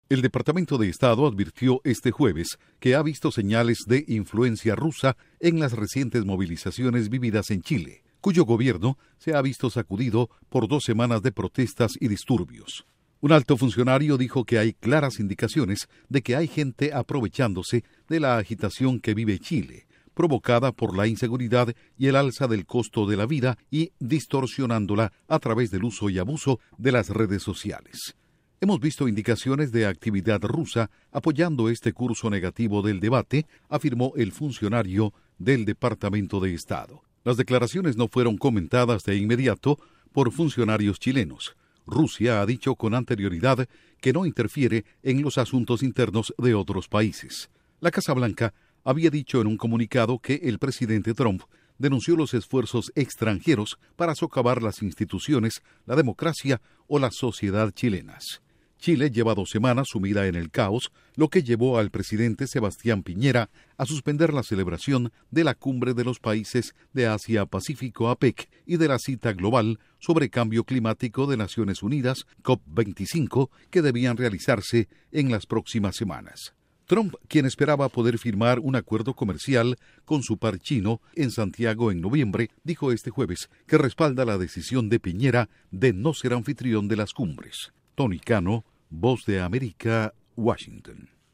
Estados Unidos advierte sobre actividad de "influencia" rusa en el conflicto en Chile. Informa desde la Voz de América en Washington